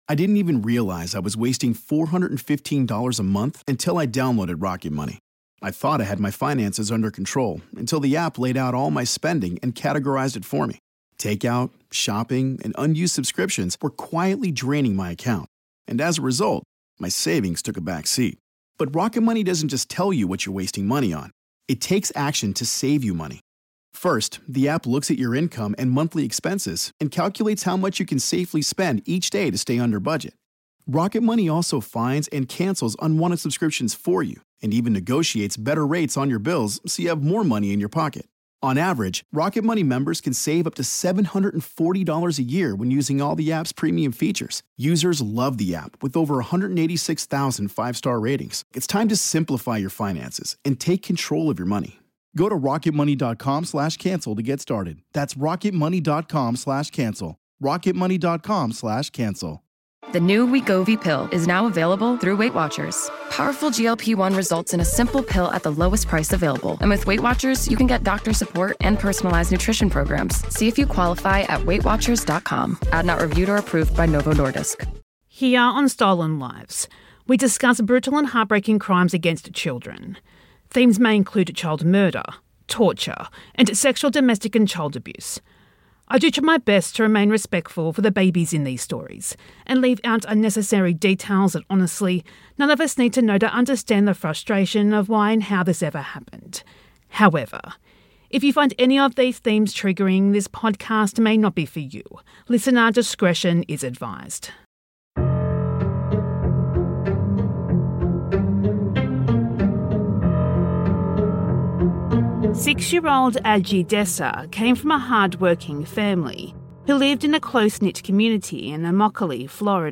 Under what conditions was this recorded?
This is a re-release with sped up audio and gaps of silence removed.